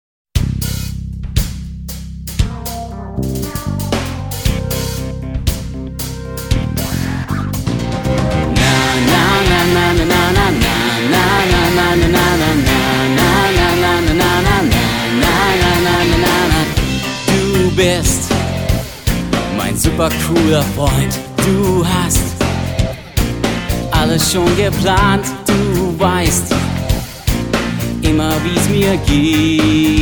• Sachgebiet: Kinderlieder